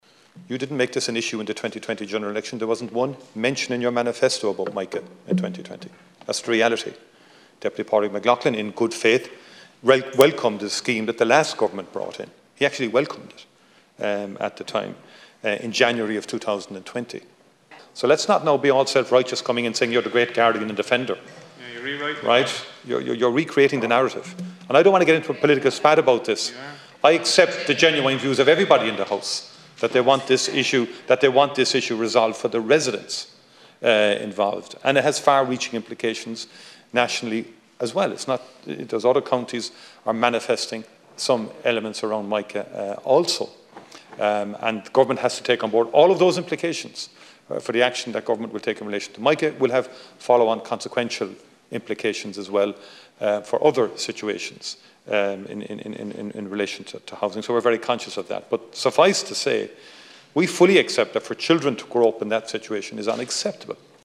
There’s been a heated debate in the Dail this afternoon on the issue – over what form the scheme will take and if the Government will grant 100% redress.
Answering questions from SF Leader Mary Lou McDonald, the Taoiseach accused the party of recreating the narrative: